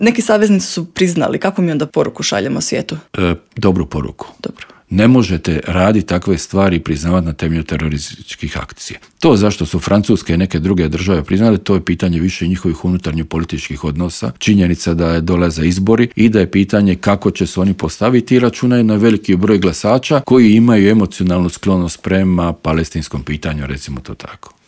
Dio je to tema o kojima smo u Intervjuu Media servisa razgovarali